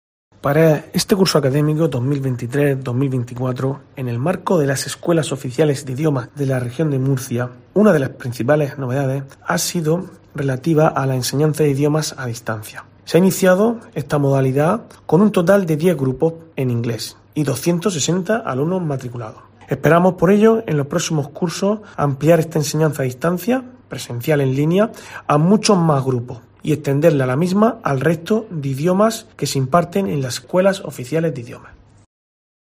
Luis Quiñonero, director general de Formación Profesional, Enseñanzas de Régimen Especial